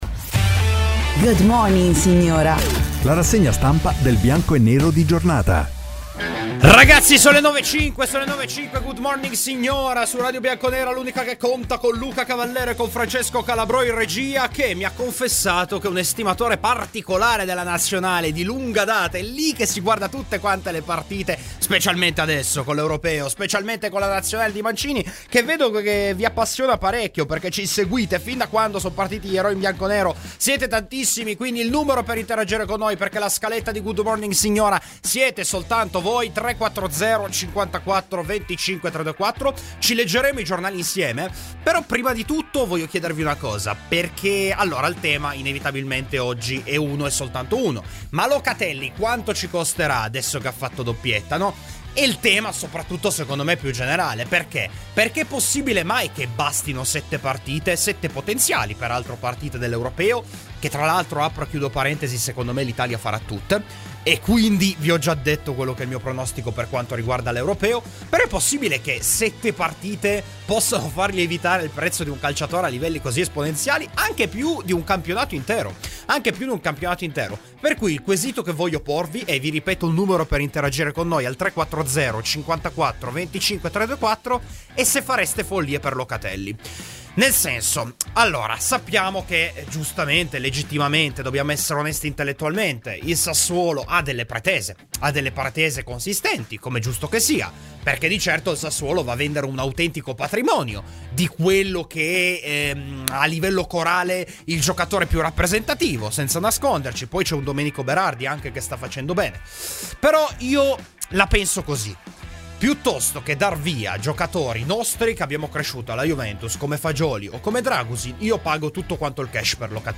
Ospite: Bruno Longhi (Mediaset) © registrazione di Radio Bianconera Facebook twitter Altre notizie